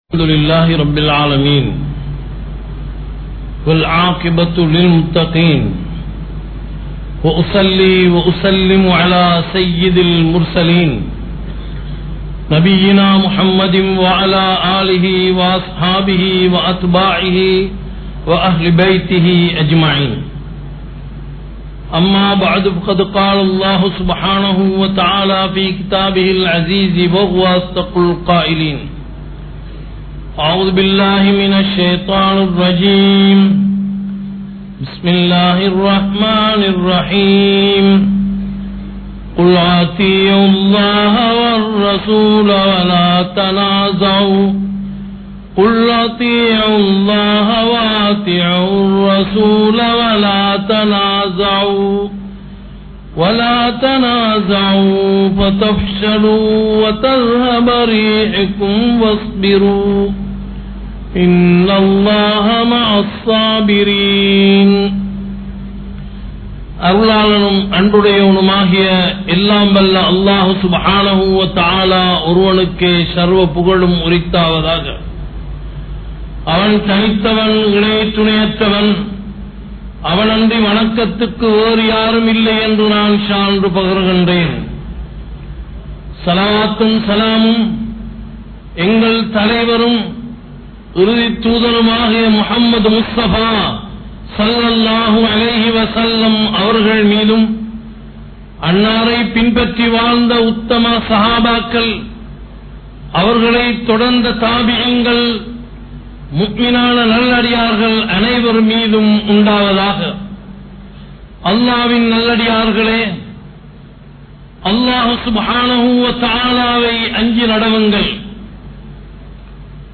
Ottrumai (ஒற்றுமை) | Audio Bayans | All Ceylon Muslim Youth Community | Addalaichenai